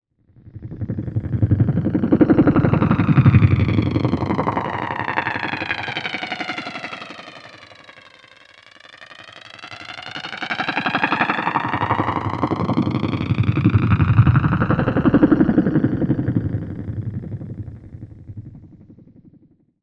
Heli FX.wav